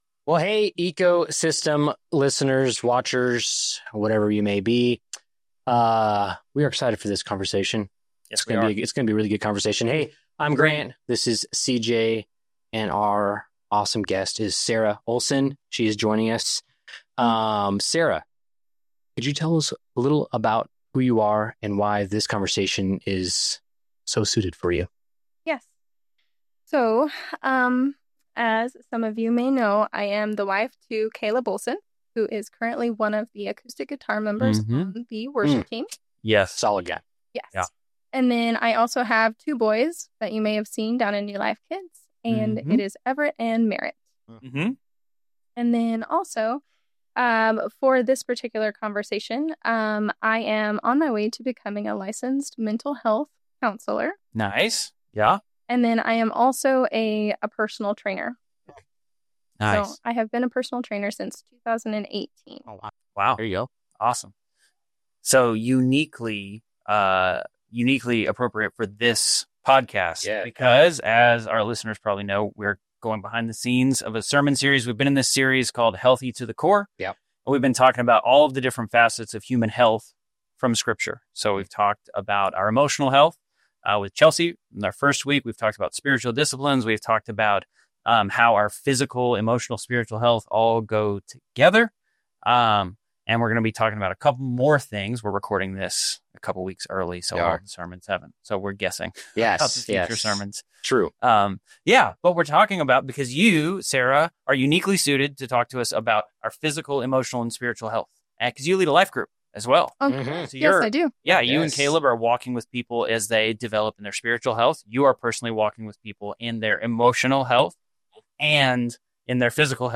This conversation is both practical and deeply encouraging